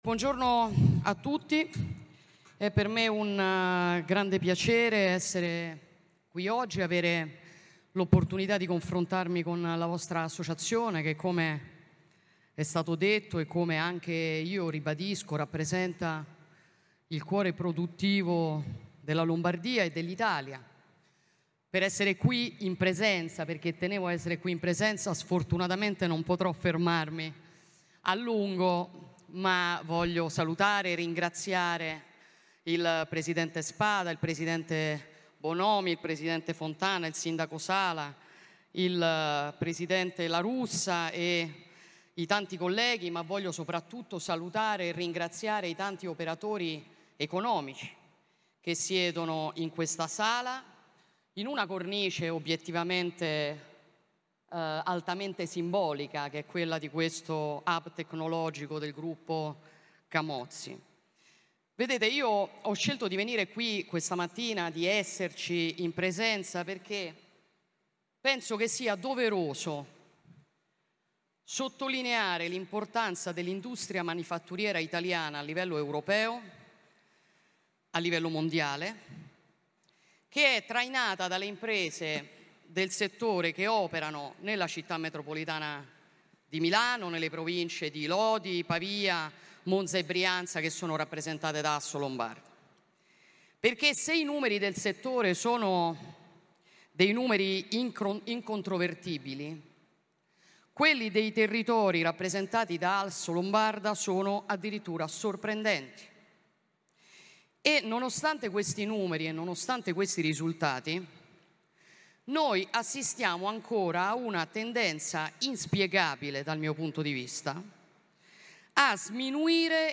Assolombarda - Intervento audio di Giorgia Meloni Presidente del Consiglio dei Ministri
intervento-audio-di-giorgia-meloni-presidente-del-consiglio-dei-ministri